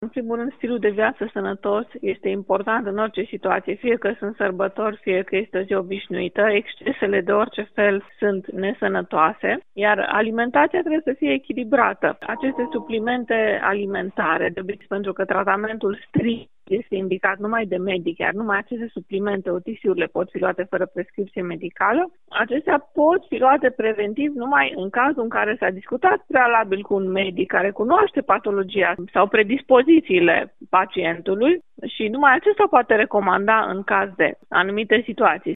medic de familie